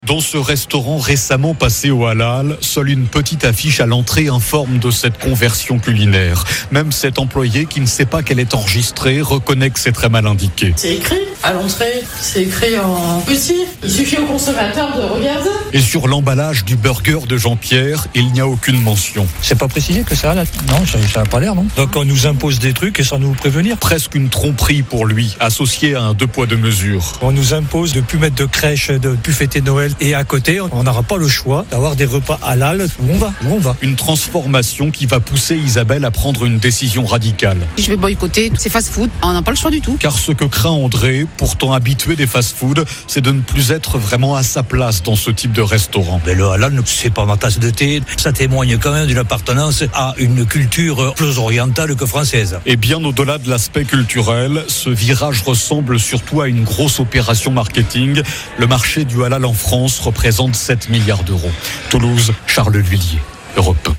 Reportage à écouter dans le podcast https